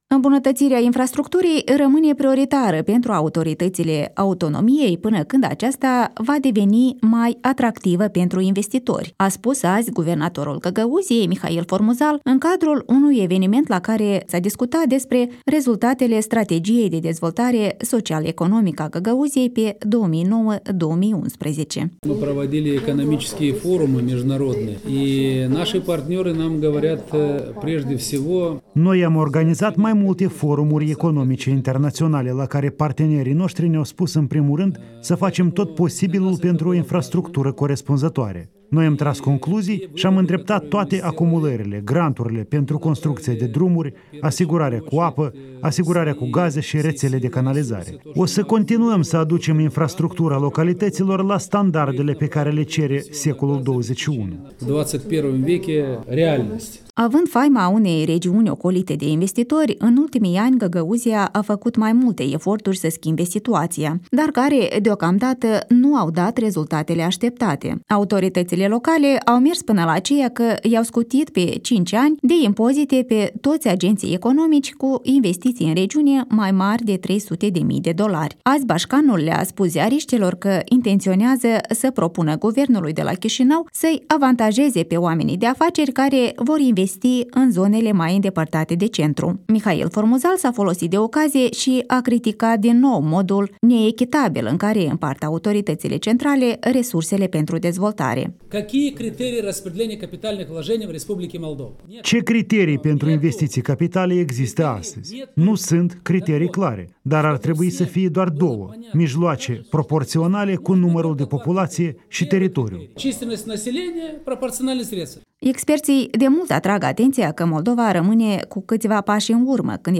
Îmbunătăţirea infrastructurii rămîne prioritară pentru autorităţile autonomiei pînă cînd aceasta va deveni mai atractivă pentru investitori, a spus azi guvernatorul Găgăuziei Mihail Formuzal la un eveniment la care s-a discutat despre rezultatele strategiei de dezvoltare social-economică a Găgăuziei pe 2009-2011: